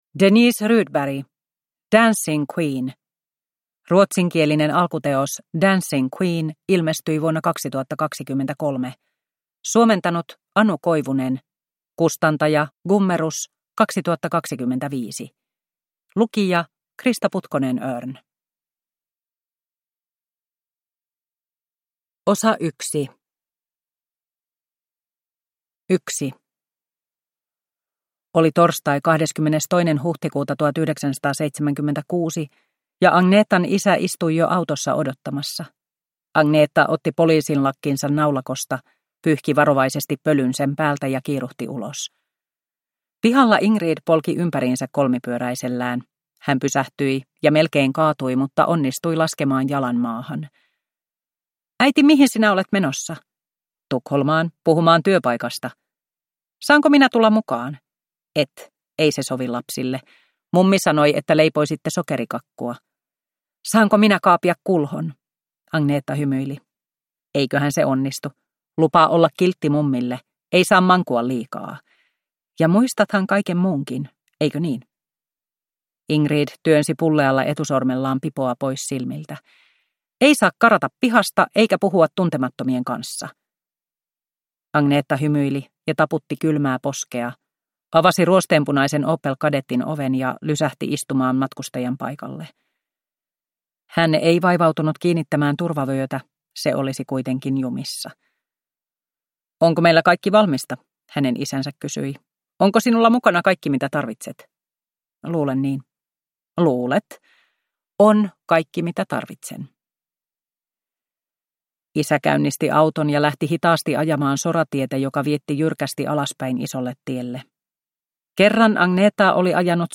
Dancing Queen – Ljudbok